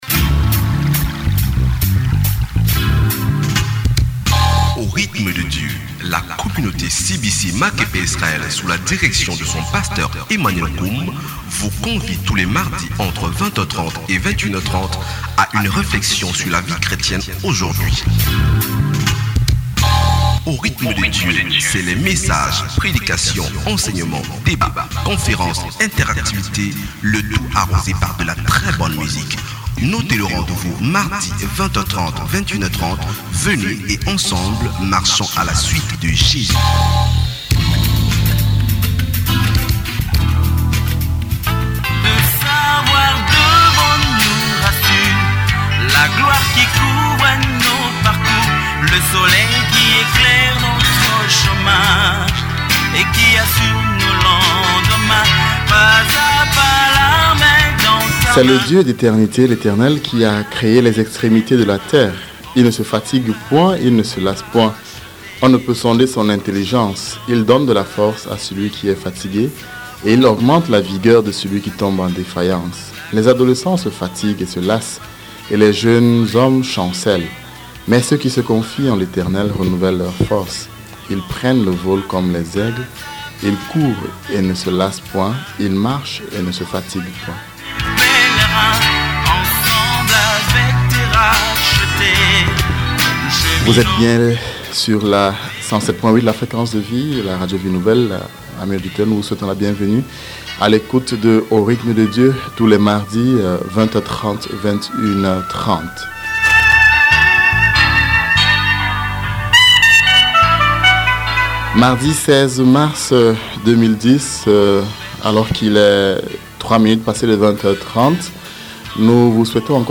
Evangelist